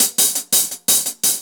Index of /musicradar/ultimate-hihat-samples/170bpm
UHH_AcoustiHatC_170-04.wav